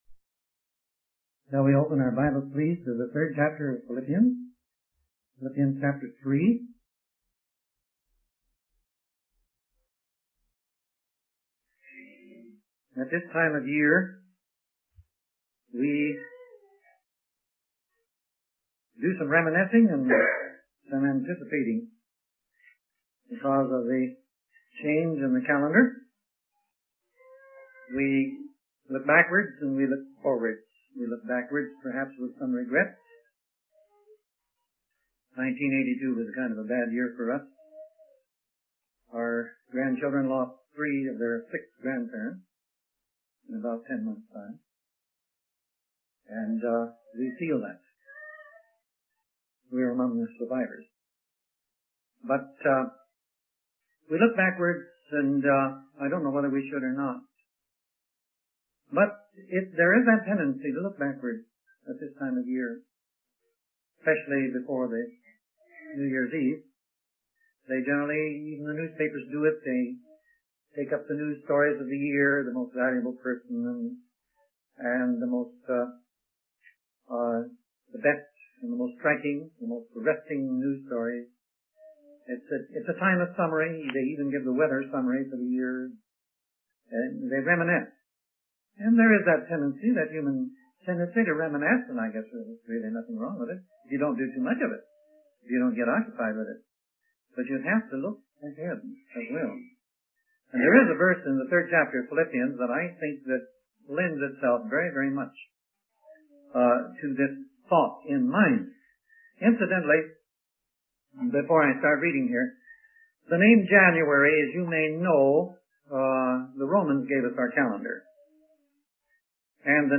In this sermon, the speaker reflects on the tendency to look backwards and reminisce at the end of the year. He shares personal experiences of loss and encourages the audience to press forward and forget the past. The speaker emphasizes the importance of service to the Lord and being a part of the body of Christ.